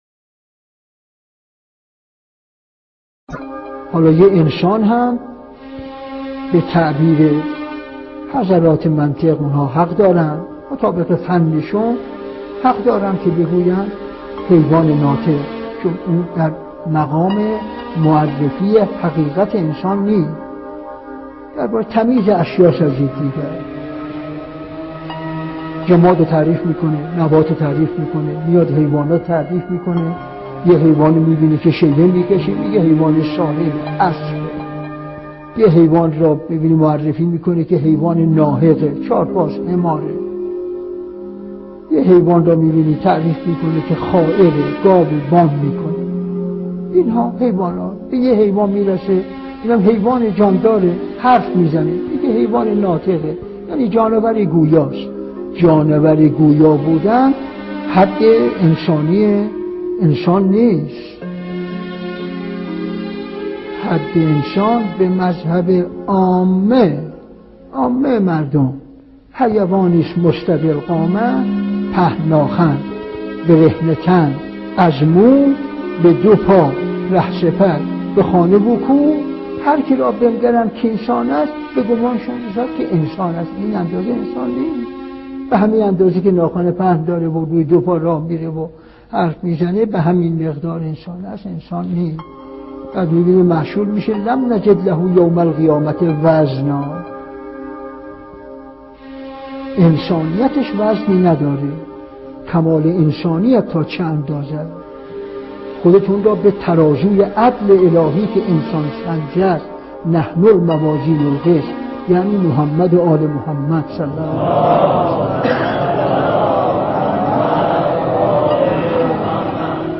به گزارش خبرگزاری حوزه، مرحوم علامه حسن زاده آملی در یکی از سخنرانی های خود به موضوع «حقیقت وجودی انسان» پرداختند که تقدیم شما فرهیختگان می شود.